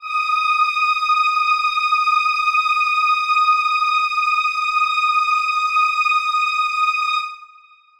Choir Piano (Wav)
D#6.wav